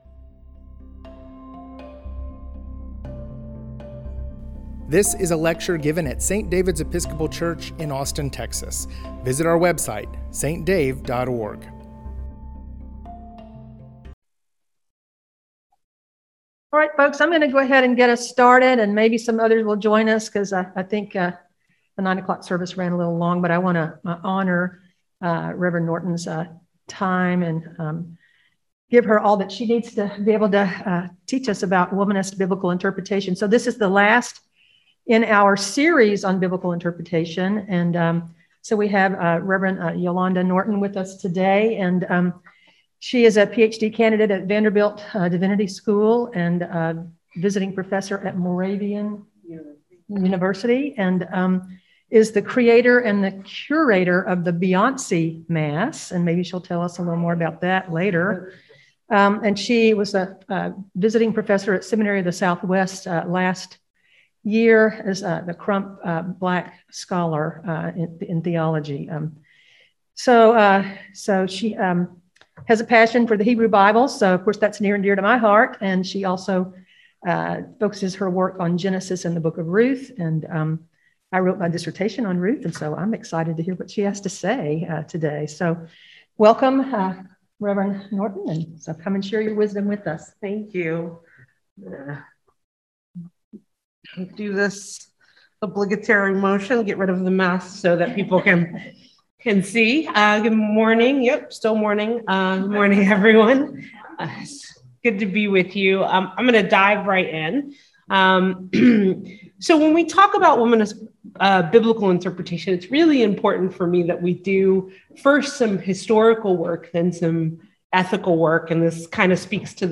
Biblical Hermeneutics Lecture Series: Womanist Biblical Hermeneutics